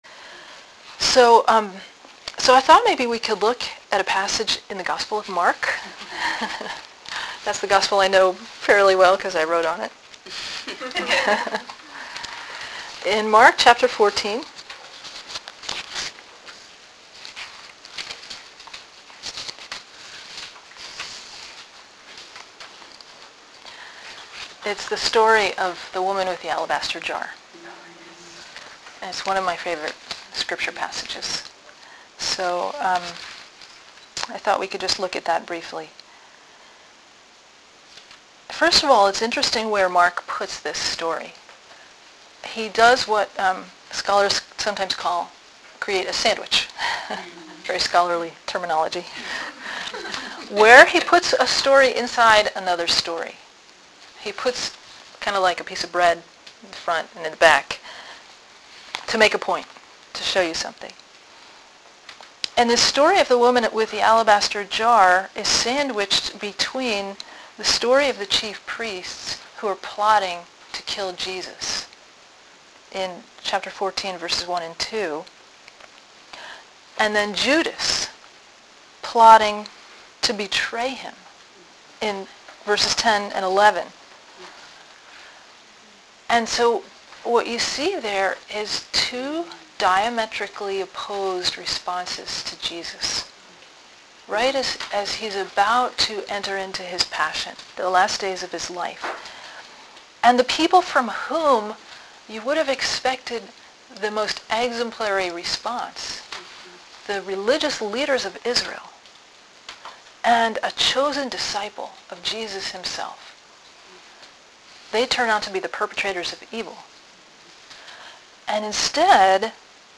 Every other month we host an Evening of Reflection for young single women.